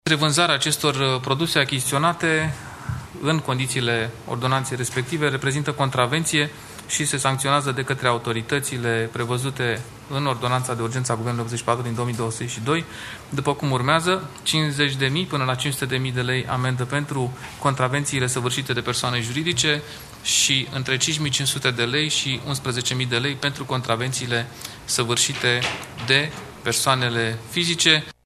Purtatorul de cuvânt al Guvernului, Dan Cărbunaru: